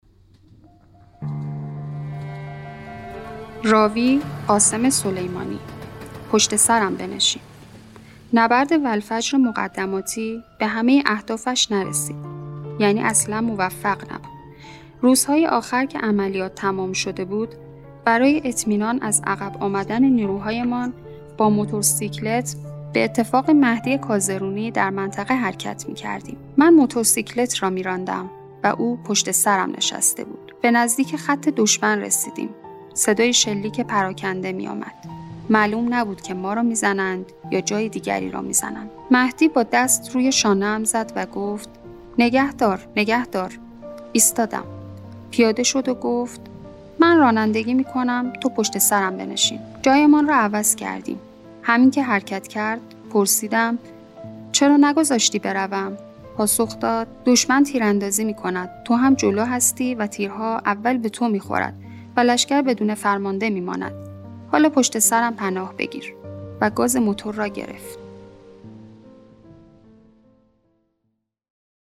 راوی: قاسم سلیمانی